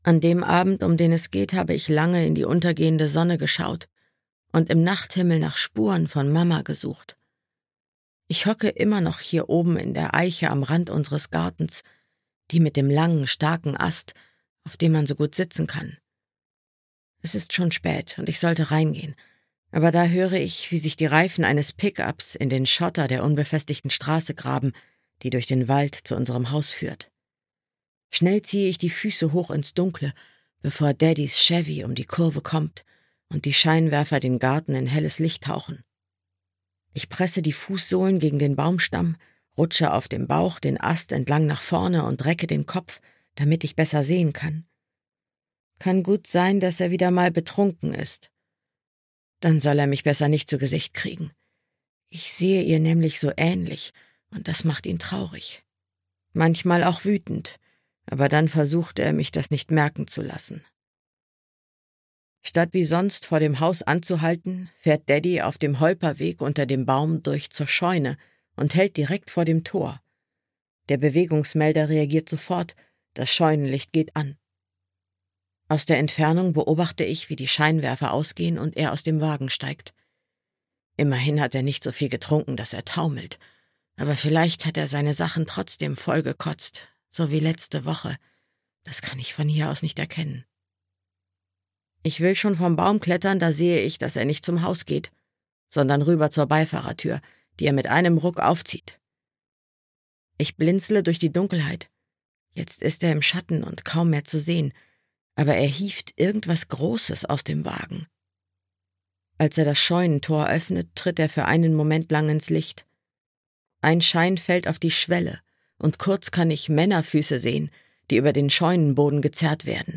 River of Violence - Tess Sharpe - Hörbuch